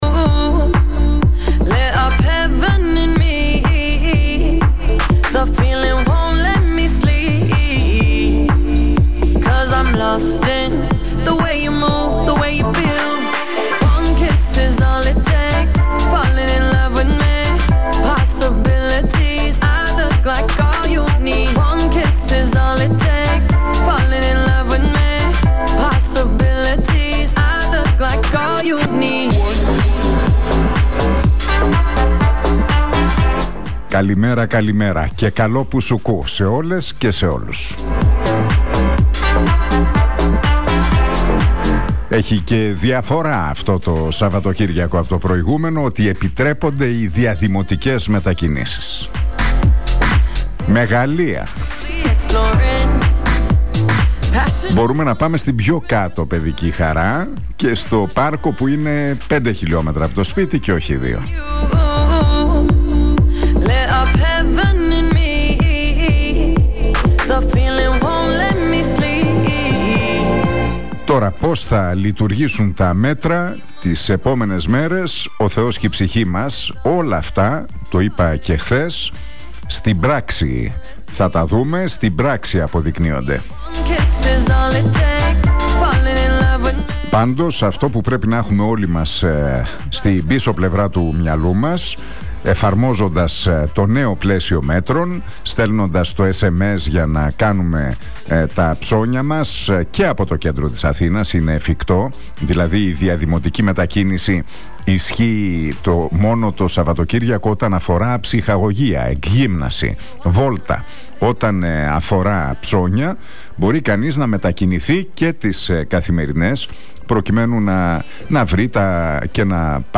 Ακούστε το σημερινό σχόλιο του Νίκου Χατζηνικολάου στον Realfm 97,8...